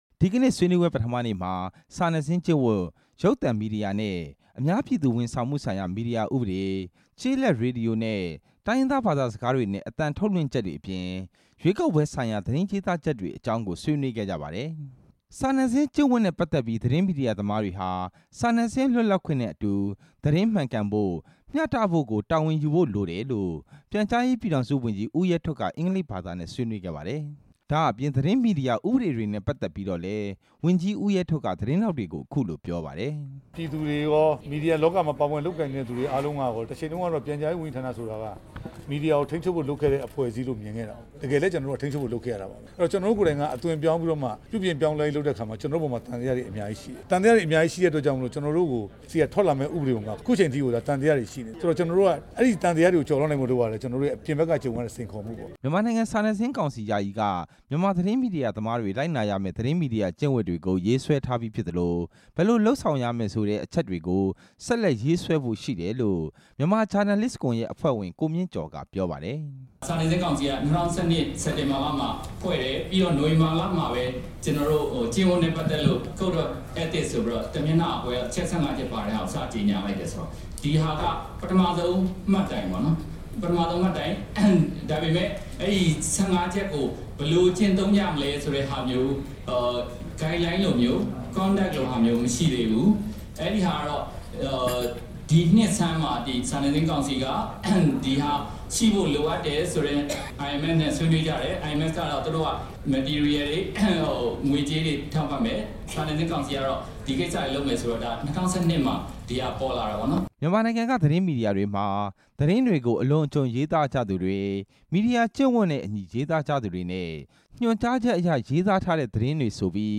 ရန်ကုန်မြို့ ချက်ထရီယံဟိုတယ်မှာကျင်းပနေတဲ့ တတိယအကြိမ်မြောက် သတင်းမီဒီယာ ဖွံ့ဖြိုး တိုးတက်ရေး ဆွေးနွေးပွဲ ပထမနေ့နားချိန်မှာ သတင်းထောက်တွေကို ပြောခဲ့တာဖြစ်ပါတယ်။